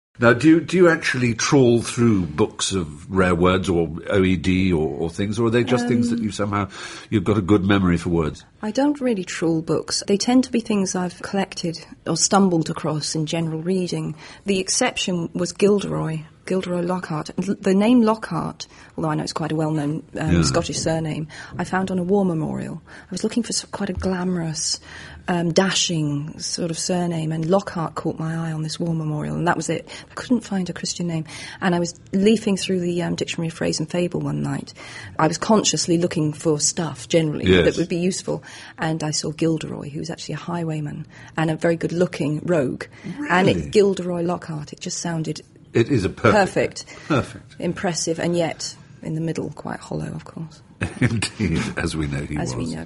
บทสัมภาษณ์ที่ตัดมาจากบีบีซี เรดิโอ 4 ซึ่งบันทึกบทสัมภาษณ์ไว้เมื่อปลายฤดูร้อนปี ค.ศ.2005 และออกอากาศในช่วงเทศกาลคริสต์มาสในเดือนธันวาคม ปี ค.ศ.2005
ฟัง เจ.เค.โรว์ลิ่ง และสตีเฟ่น ฟราย พูดคุยเกี่ยวกับแรงบันดาลใจเบื้องหลังชื่อของกิลเดอรอย ล็อกฮาร์ต